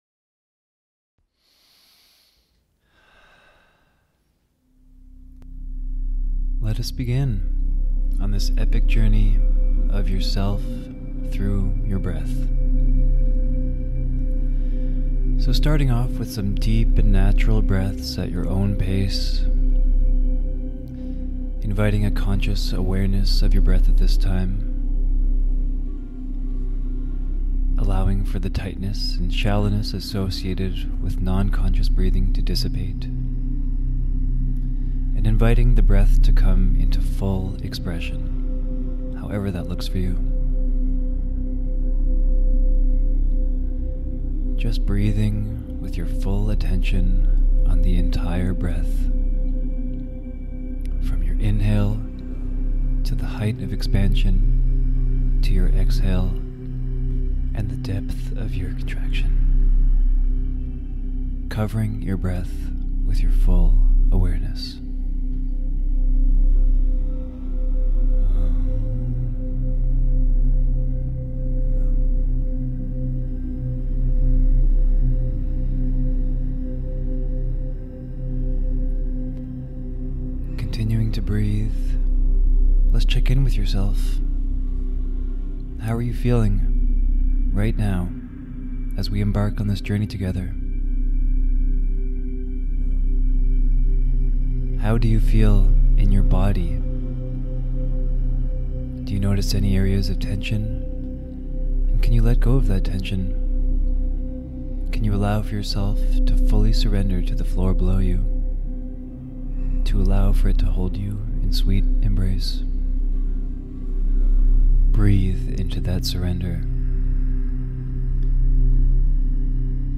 Energy Alignment Guided Breathwork 55 minutes - YouTube-01.mp3